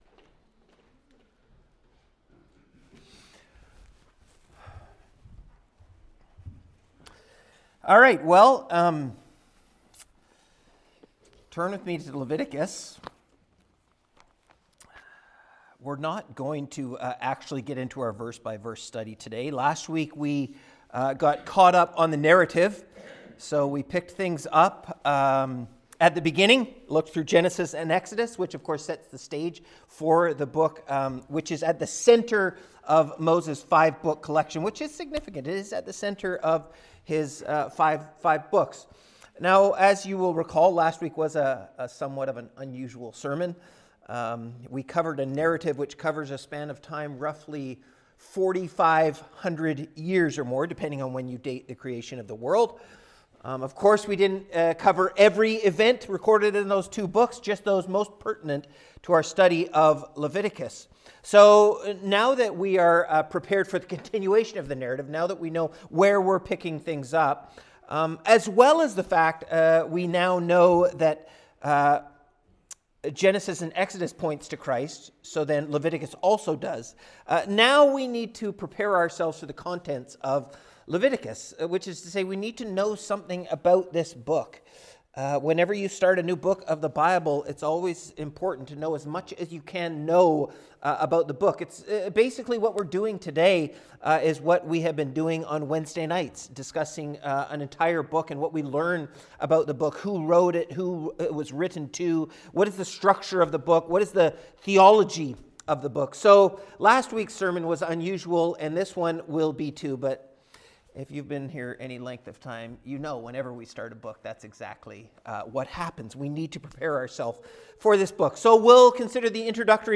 Halifax Reformed Baptist Sermons